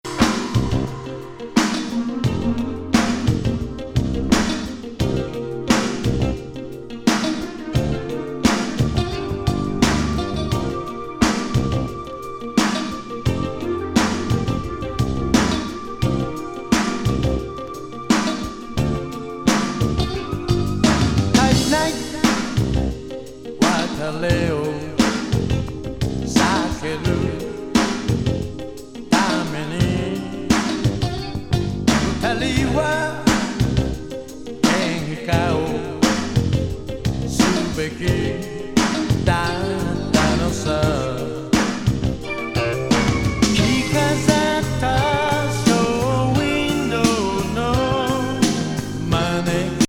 ハイエナ・ディスコ歌謡